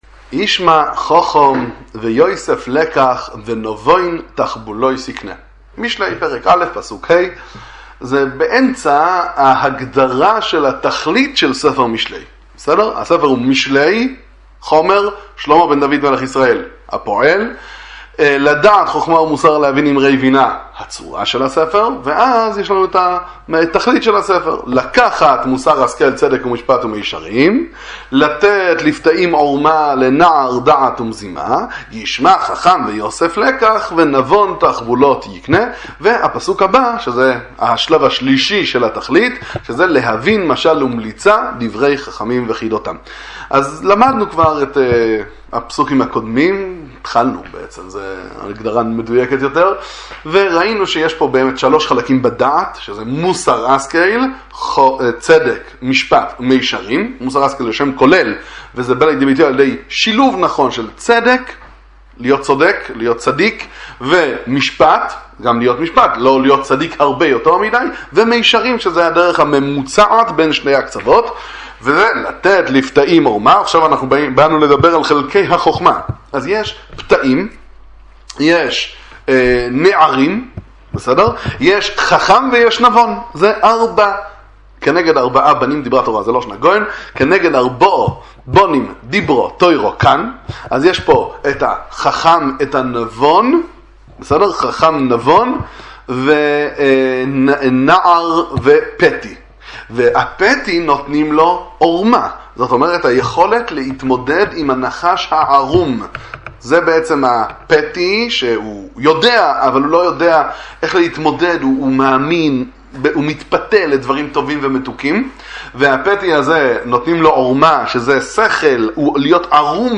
דברי תורה ומוסר מהגאון מוילנא, שיעורי תורה בכתבי הגר"א